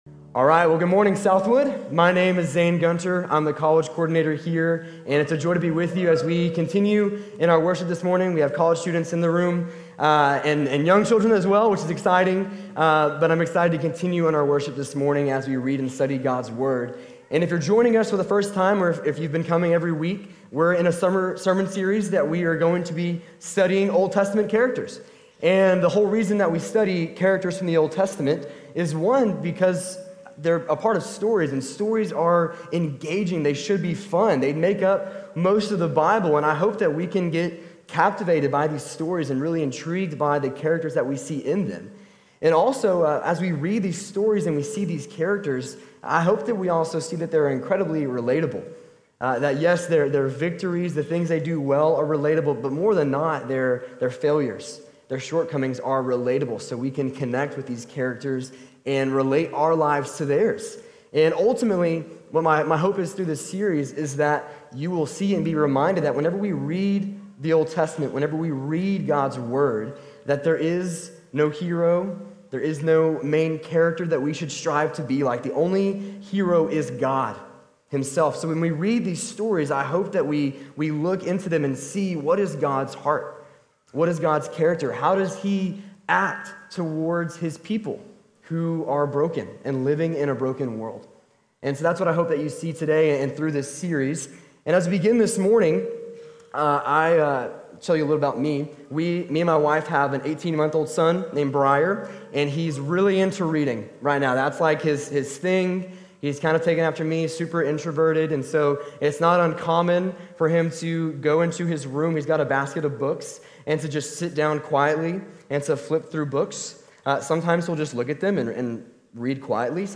How to Handle Hard Times | Sermon | Grace Bible Church